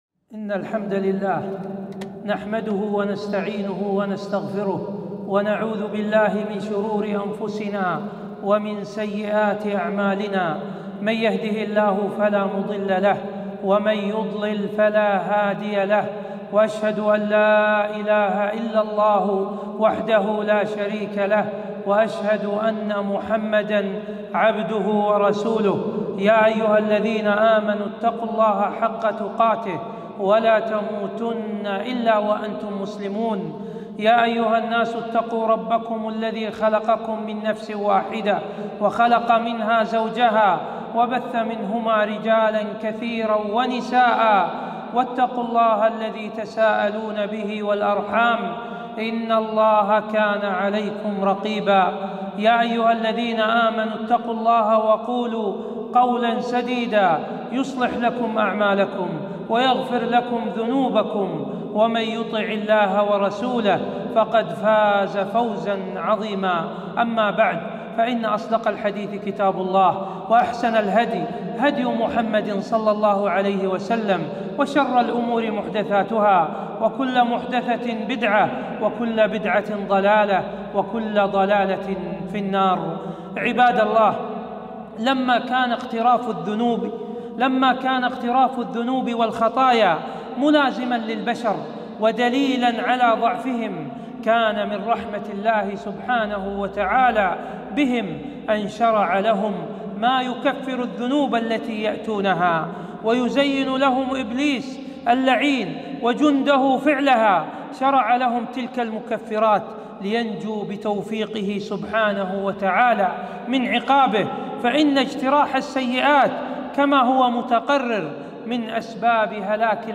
خطبة - مكفرات الذنوب